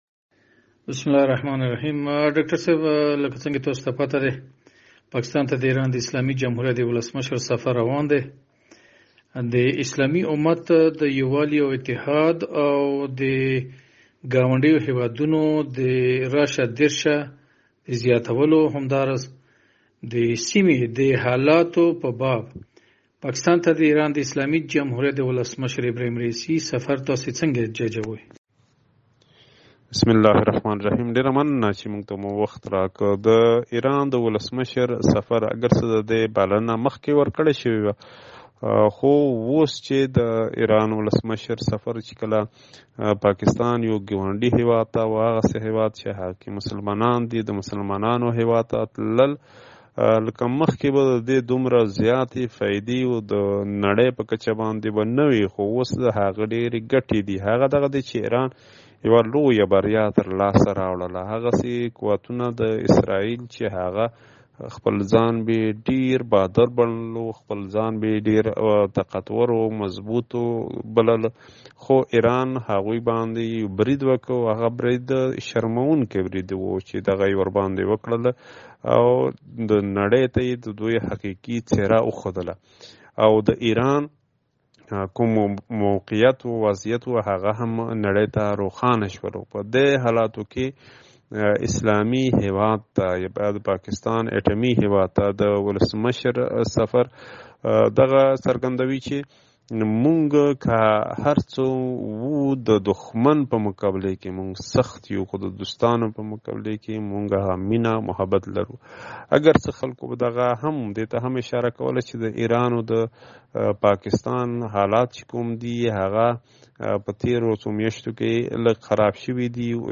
بچوې: ایران رهبر ، پاکستان شیعه ، فلسطینیانو ، ټلویزونی مرکه ، ګاونډیان ، ریښتوني ژمنه ، طوفان الاقصی